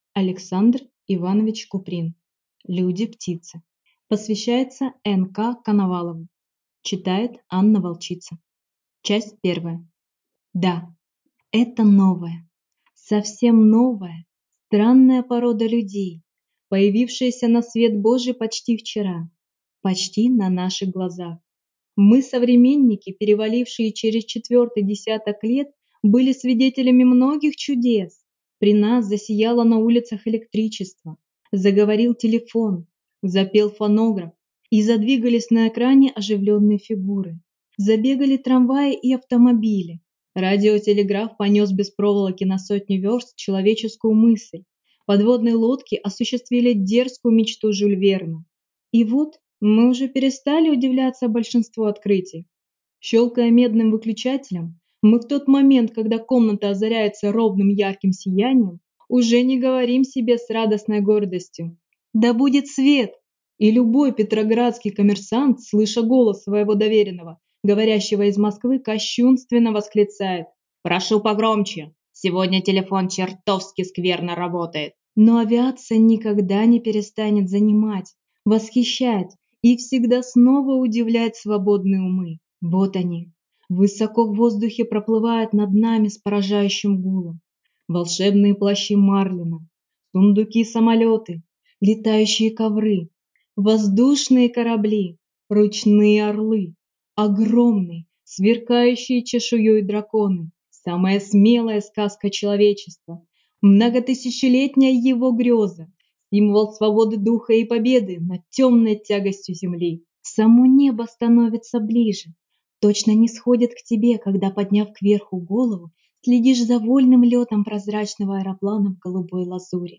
Аудиокнига Люди-птицы | Библиотека аудиокниг